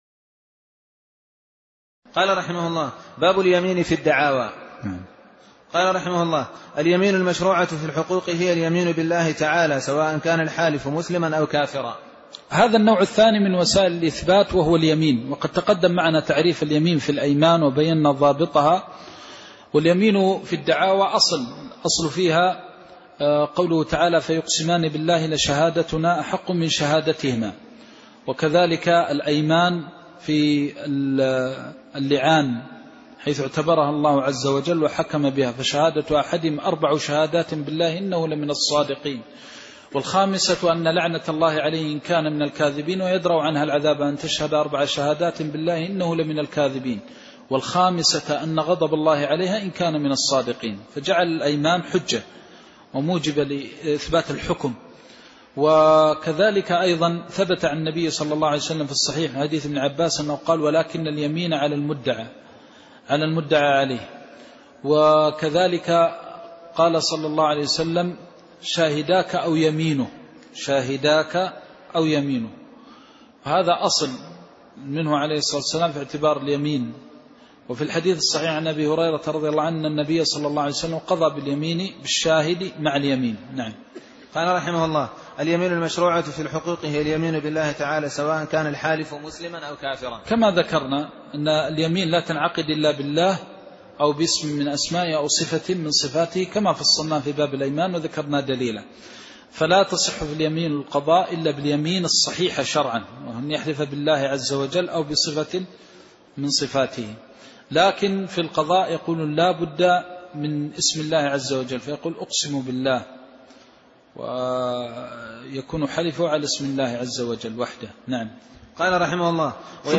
المكان: المسجد النبوي الشيخ: فضيلة الشيخ د. محمد بن محمد المختار فضيلة الشيخ د. محمد بن محمد المختار باب اليمين في الدعاوي (04)